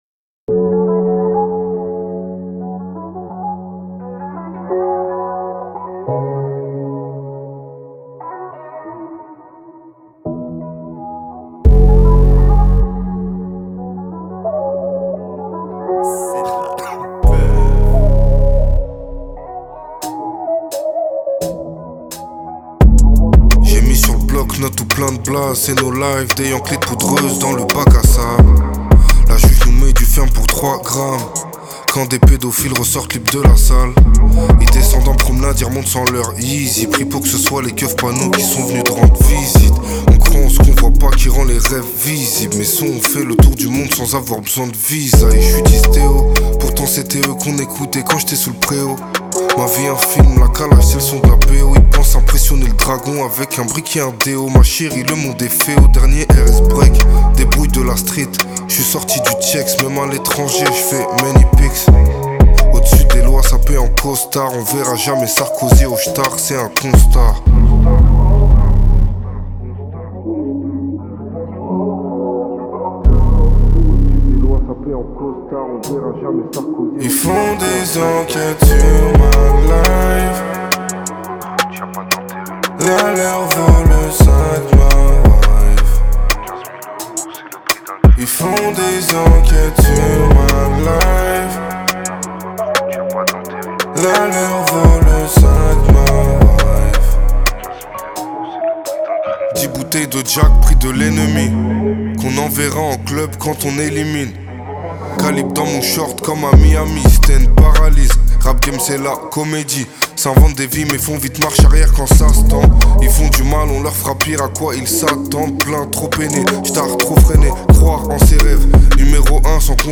Genres : french rap, pop urbaine